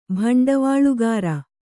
♪ bhaṇḍavāḷugāra